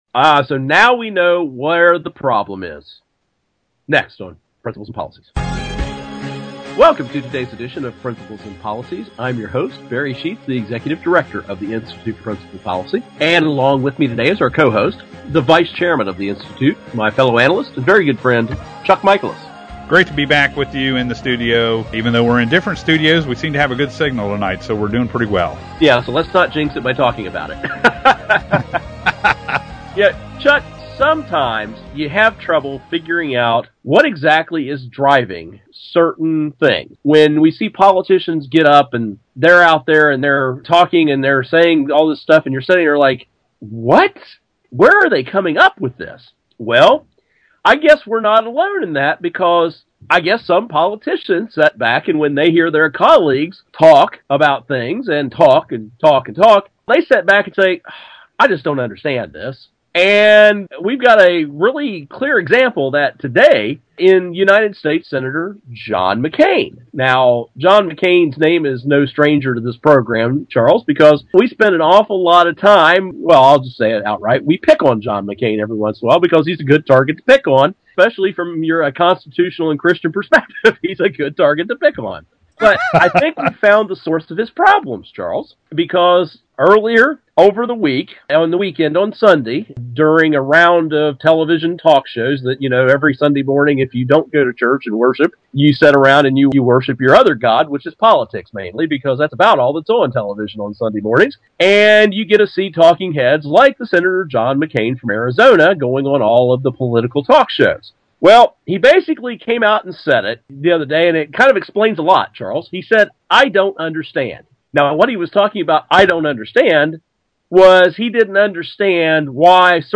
Our Principles and Policies radio show for Tuesday April 9, 2013.